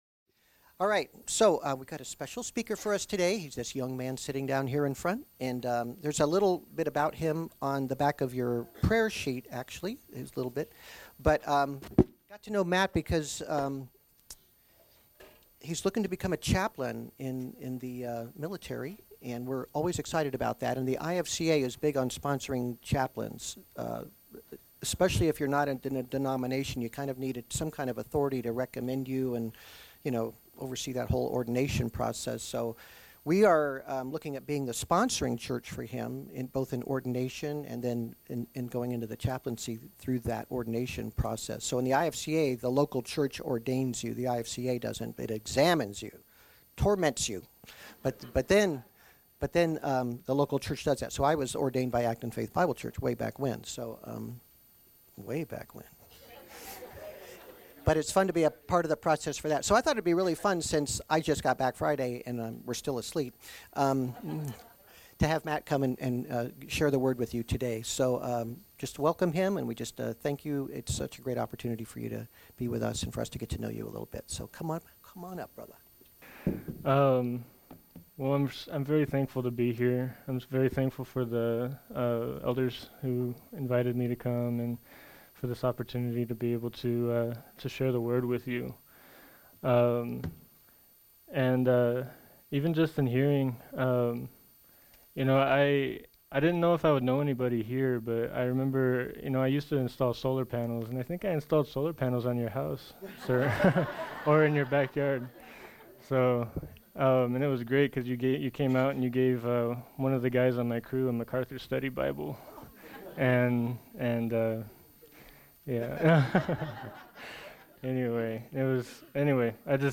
Speaker